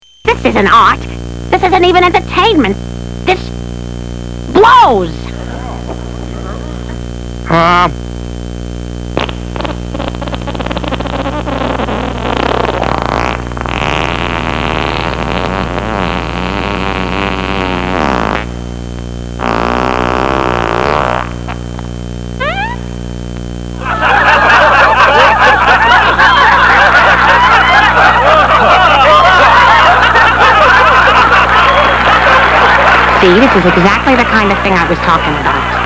Click here to Download Peter Griffin Farting!
farting.mp3